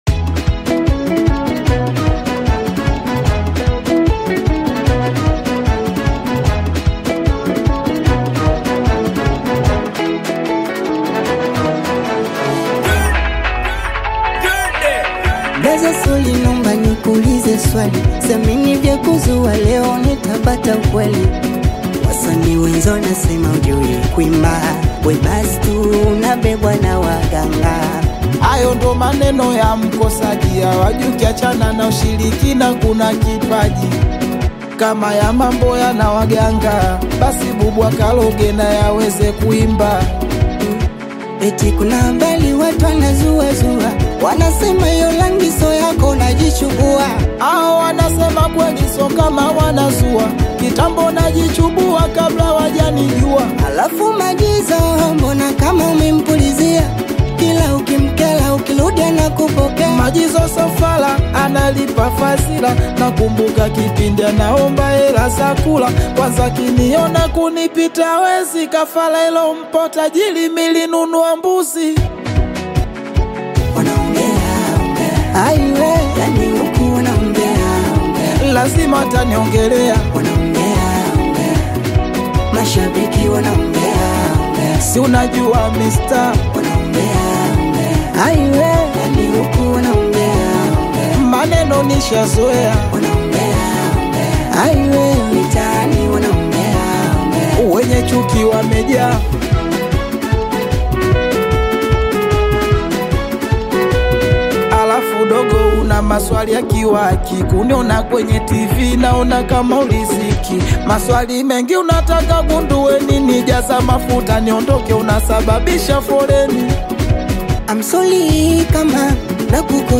Tanzanian bongo flava singeli singer
Singeli You may also like